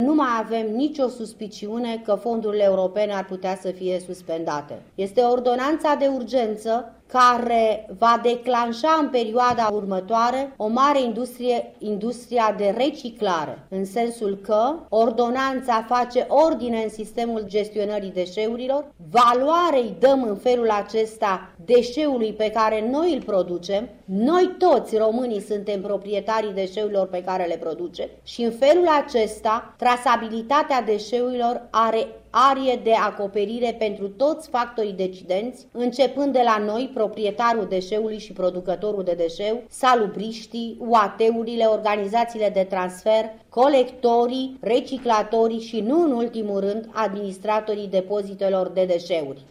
Graţiela Gavrilescu a vorbit şi despre noile prevederi referitoare la colectarea, depozitarea şi reciclarea deşeurilor: